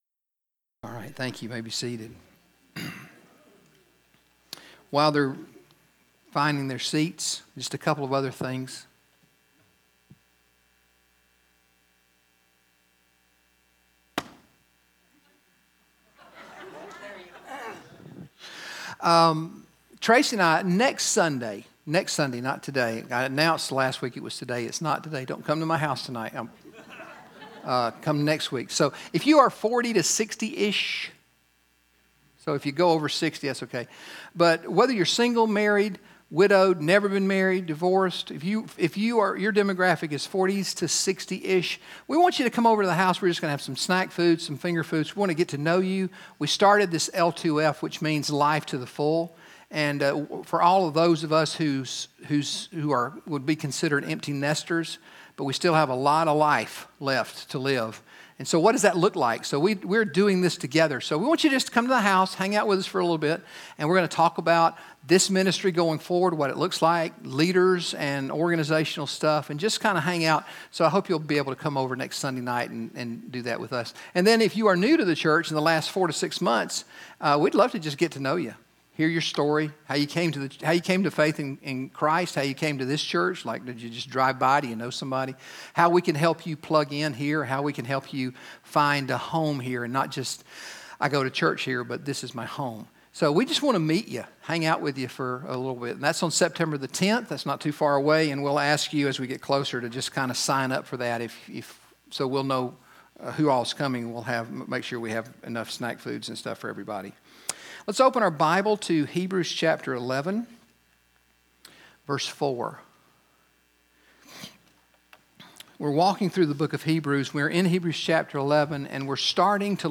GCC Sermons | Gwinnett Community Church Sermons